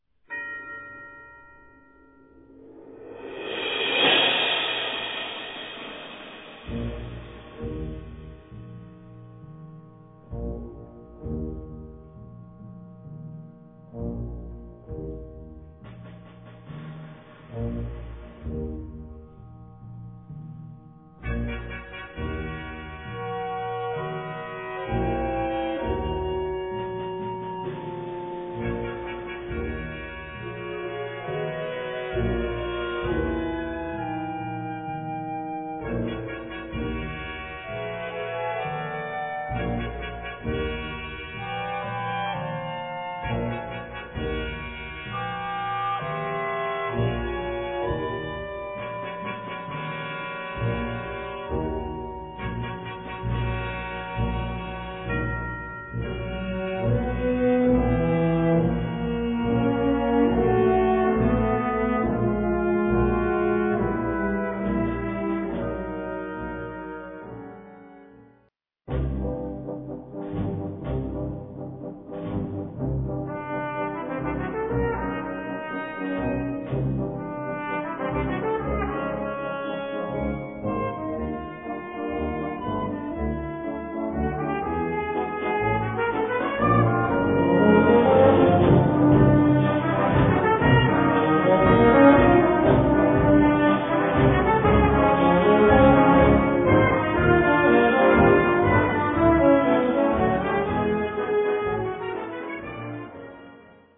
Kategorie Blasorchester/HaFaBra
Unterkategorie Konzertmusik
Besetzung Ha (Blasorchester)
beginnt geheimnisvoll und misteriös, gar nicht scherzhaft.